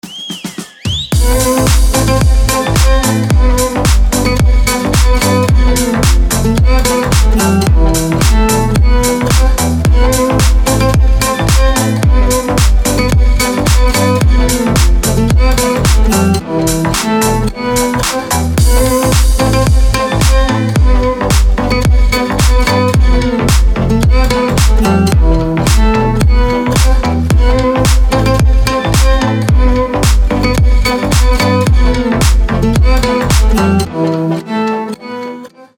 • Качество: 320, Stereo
гитара
свист
deep house
восточные мотивы
без слов
красивая мелодия
Превосходный турецкий дипчик.